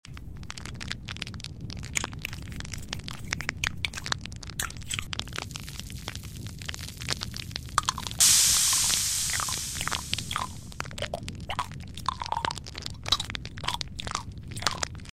Tung Tung Tung Sahur ASMR. sound effects free download
Mp3 Sound Effect Tung Tung Tung Sahur ASMR. Delicious COALS.